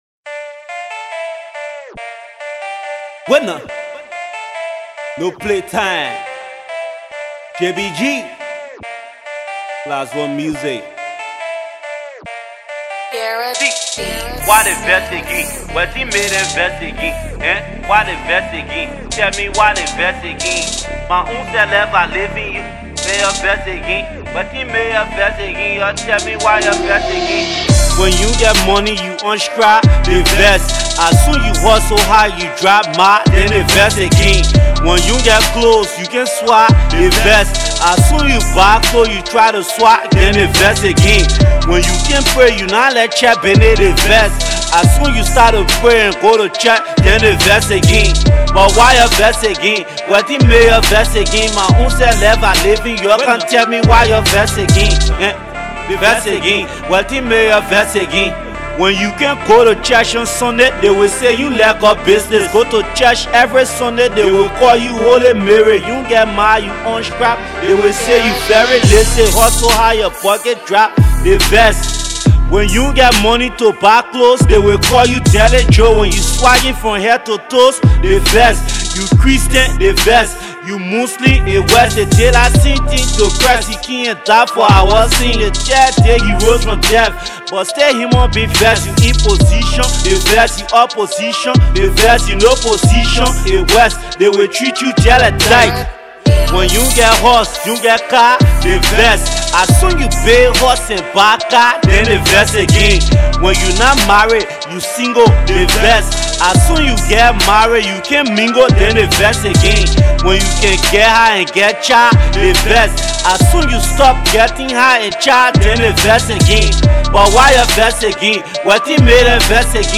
Gosple
fire gospel rap
Fine rap Enjoy!